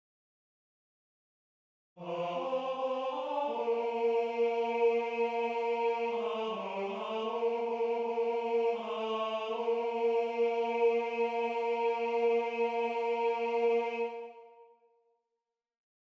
Key written in: B♭ Major
How many parts: 4
Type: Barbershop